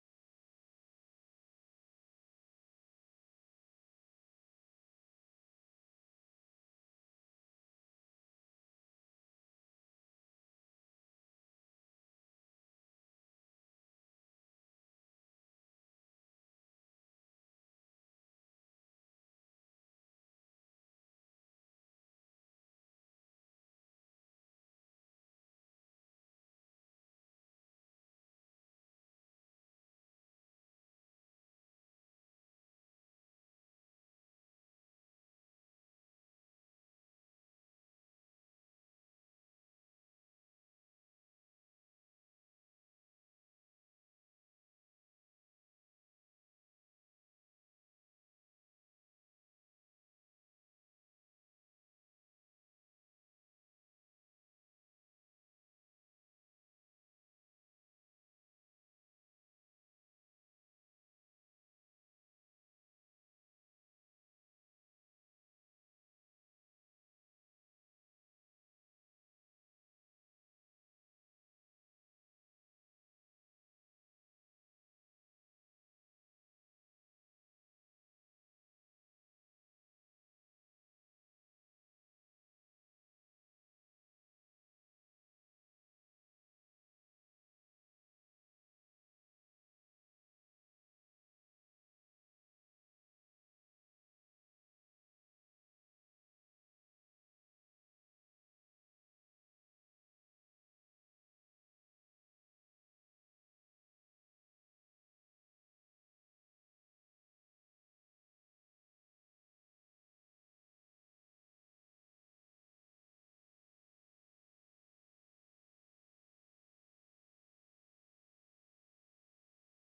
Praise and Worship from November 10th 2024